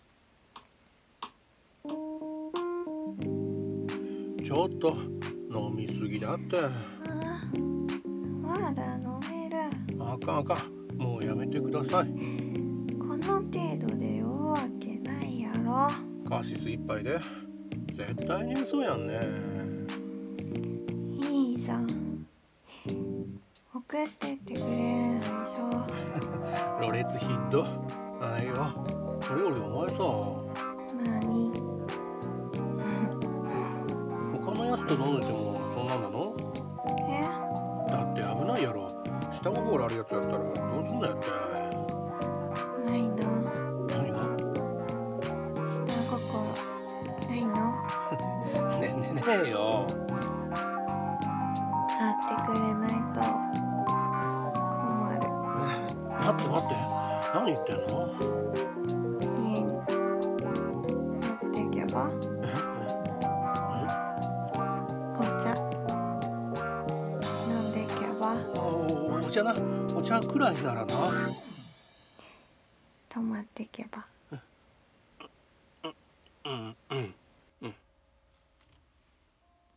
【 下心 】 掛け合い 声劇